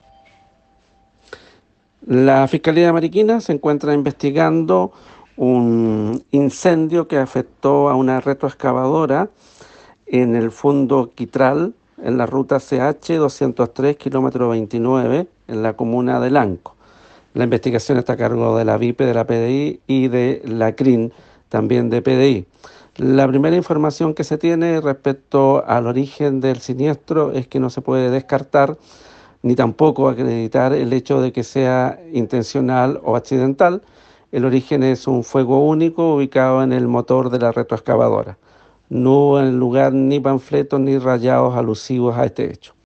Fiscal Alejandro Ríos..